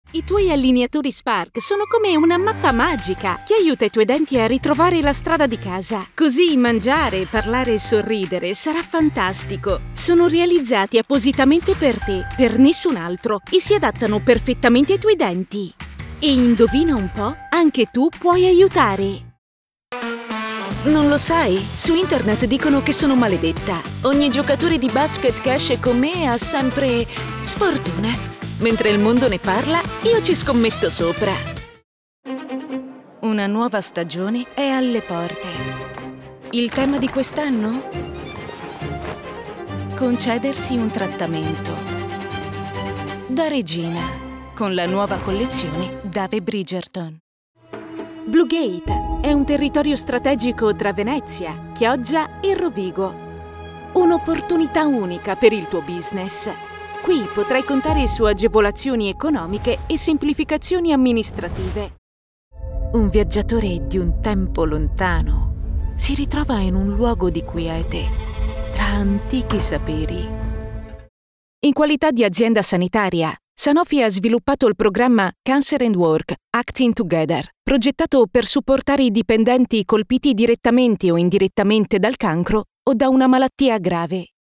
Never any Artificial Voices used, unlike other sites.
Yng Adult (18-29) | Adult (30-50)